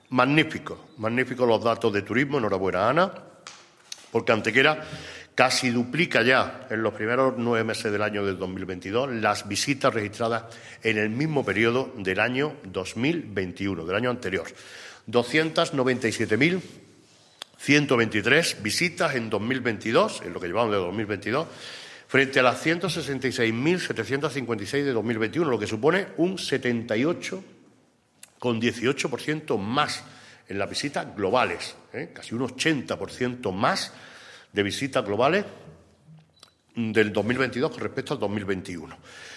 El alcalde de Antequera, Manolo Barón, y la teniente de alcalde delegada de Turismo, Ana Cebrián, han informado hoy en rueda de prensa sobre los datos más relevantes que ha dado de sí el turismo en nuestra ciudad entre los meses de enero y septiembre del presente año 2022.
Cortes de voz